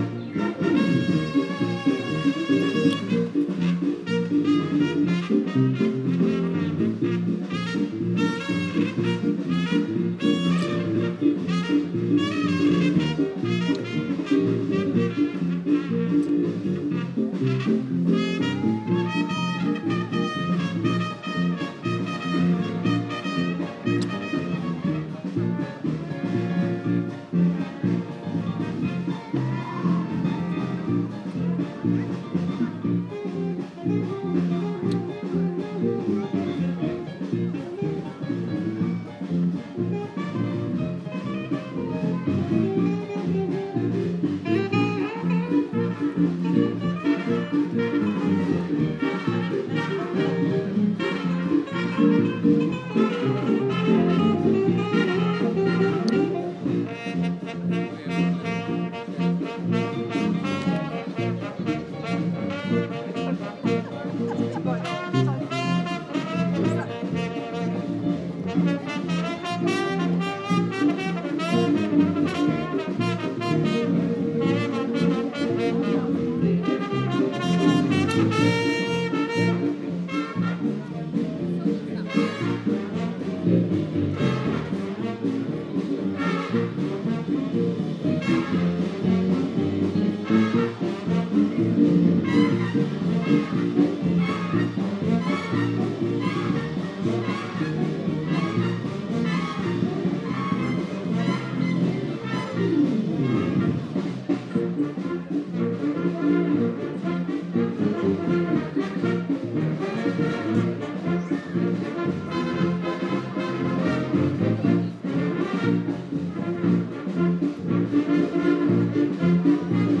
First tune of second set with various solos